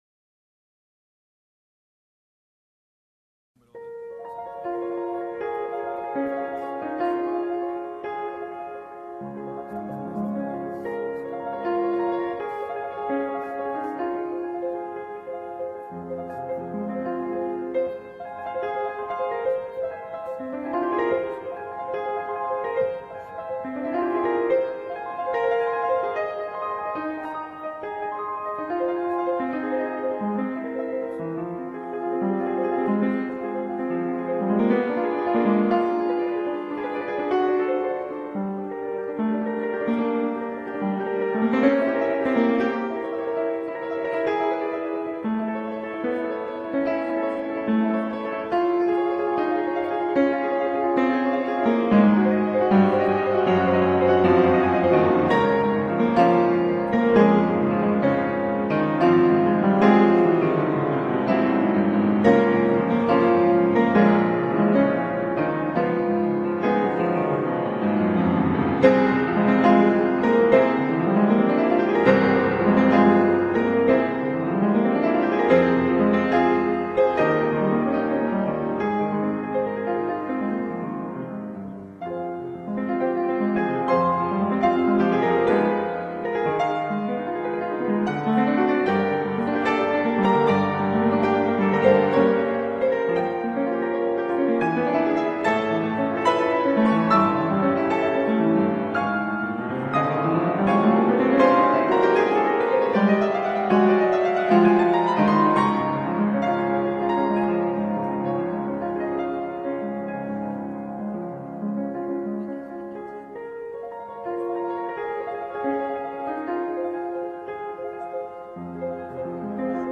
1. The constant fast-paced movement in the right hand sounds like flowing water.
2. Eventually, the fast-paced movement shifts to the left hand.
3. There’s no extended beginning or ending; it just appears, flutters around happily, and wraps up.